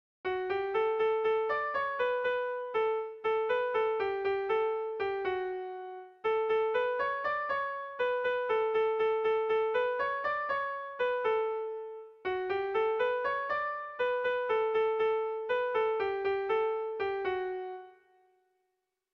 Seiko handia (hg) / Hiru puntuko handia (ip)
A1BA2